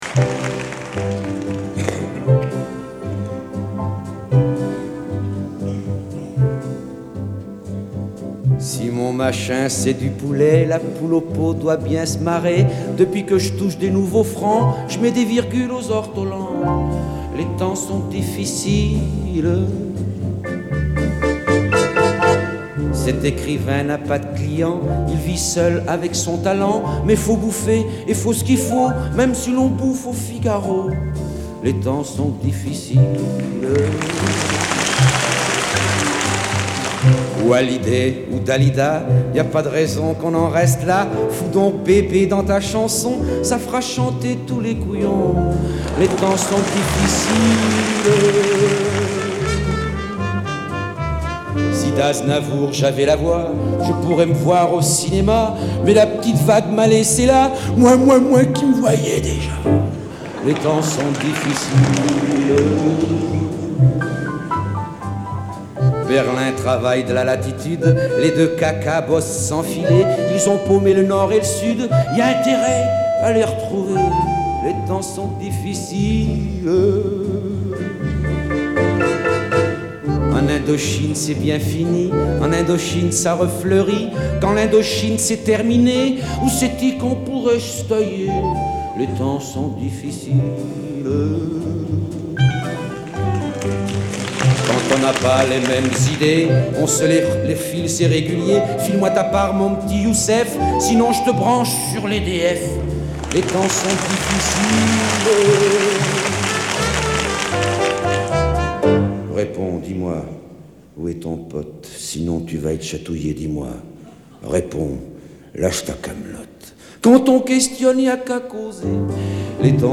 en feignant une voix éteinte